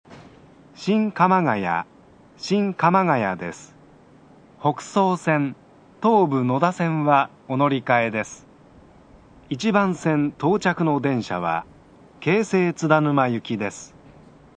◆主要駅タイプ２（新鎌ヶ谷にて）
クリアーな音質に、音量も箱形に比べ大きくはっきりと聞こえる。
スピーカー：TOA大
新鎌ヶ谷１番線 接近メロディー+放送（男性）